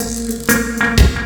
FXLOOP 02 -L.wav